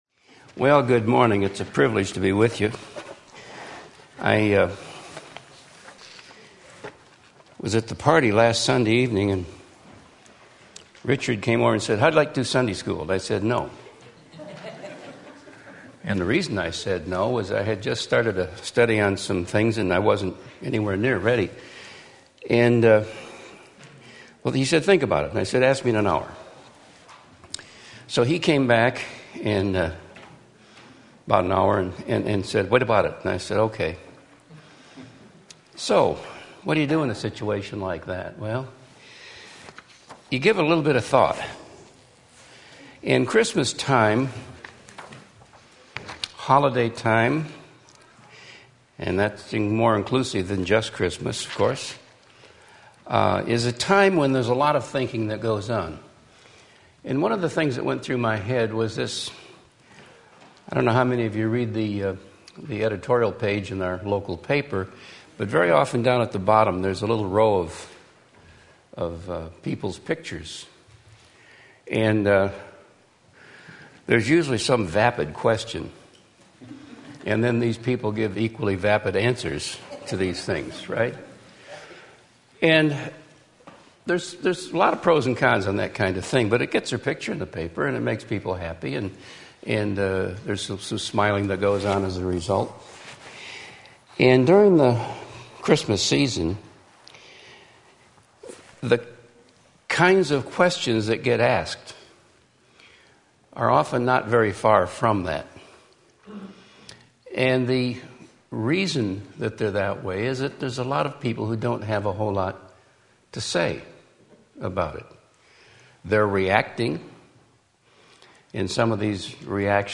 Christmas Talk Sunday School